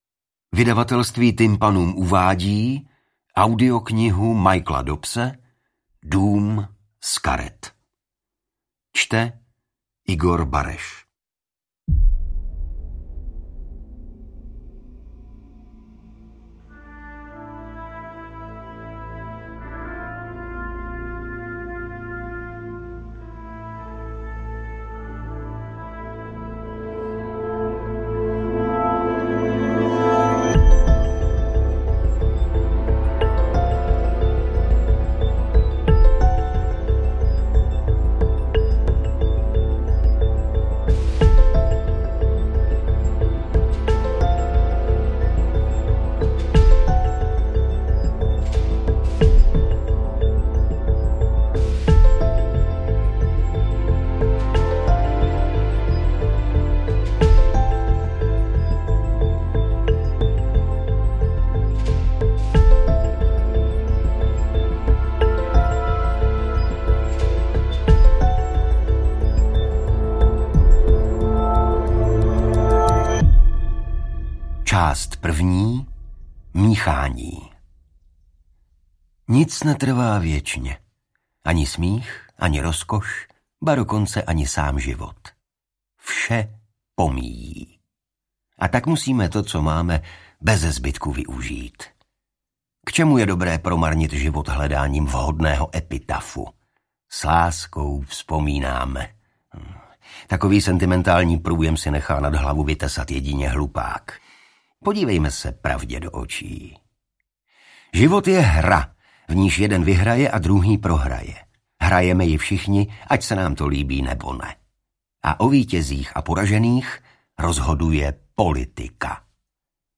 Interpret:  Igor Bareš